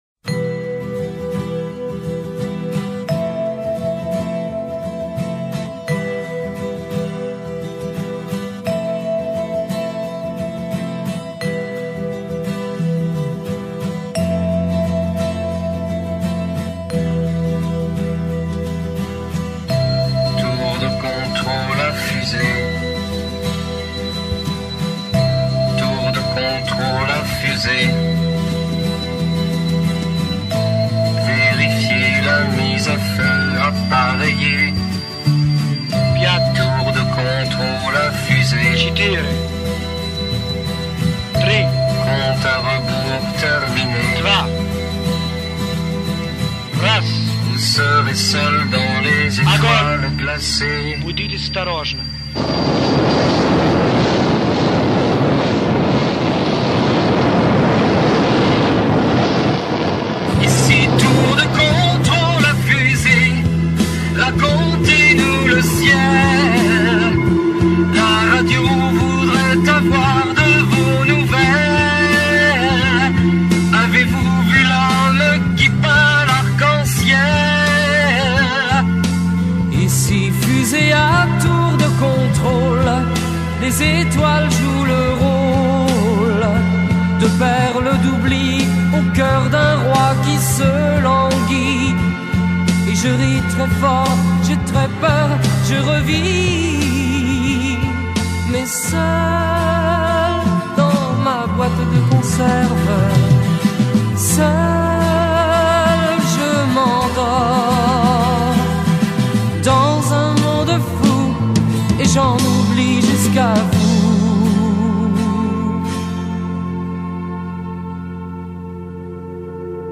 comme un décompte en russe au début de la chanson